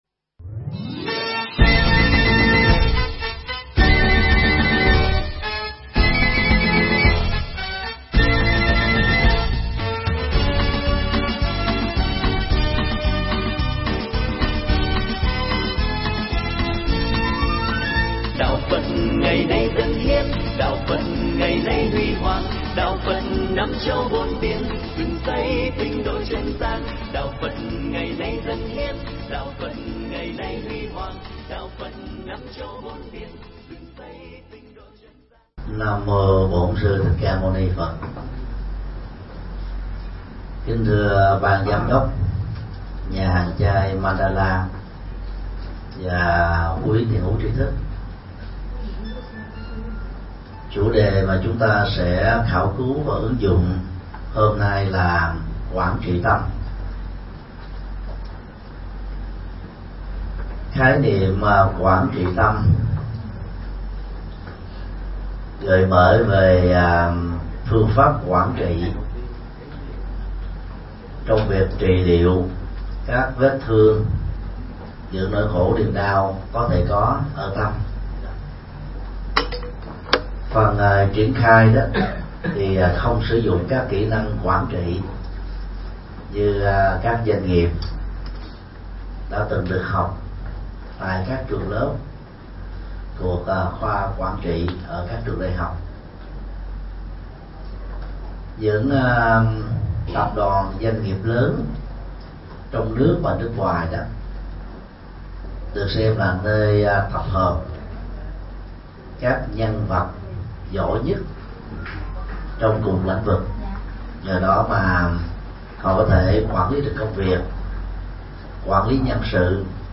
Mp3 Thuyết Giảng Quản trị Tâm
Giảng tại Nhà hàng chay Mandala